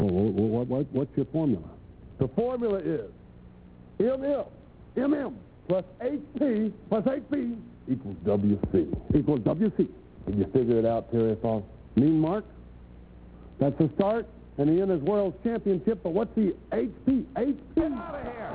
The interviewer is none other than Terry Funk and yes, he really was a baby here folks!
He sounds NOTHING like he does as the undertaker...i'll warn you all now.